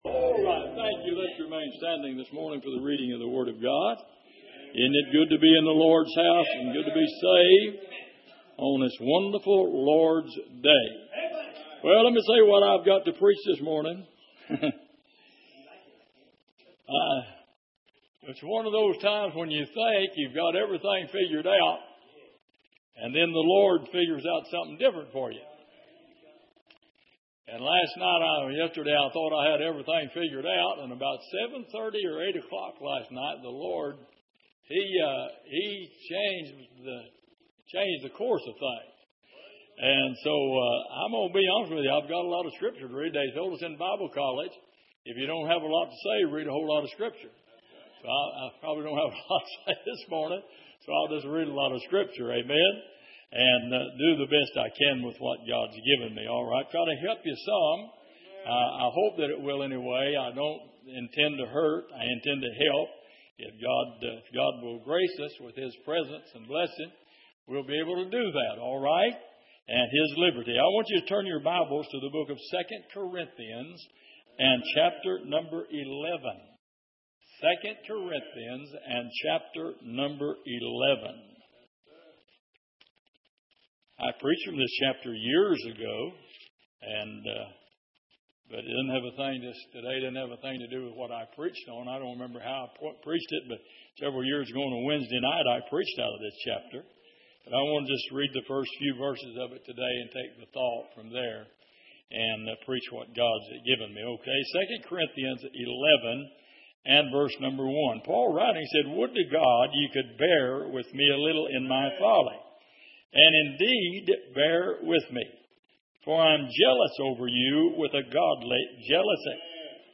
Passage: 2 Corinthians 11:1-4 Service: Sunday Morning